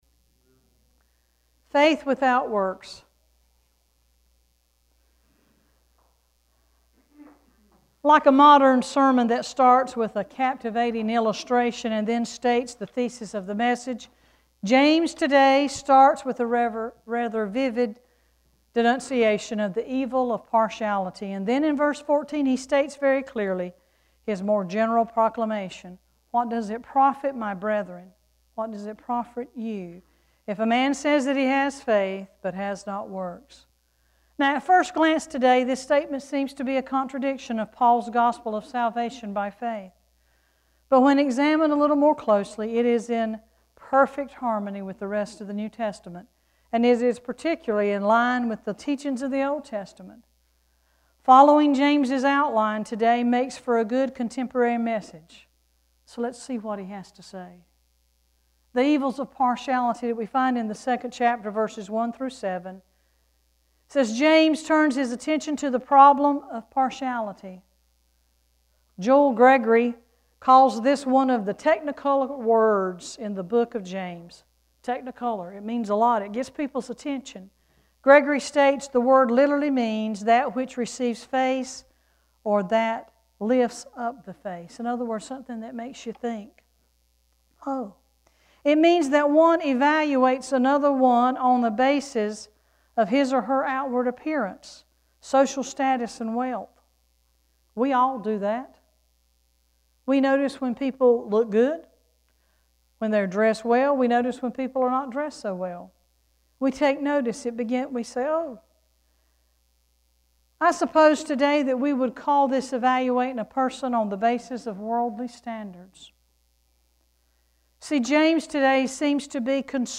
9-6-sermon.mp3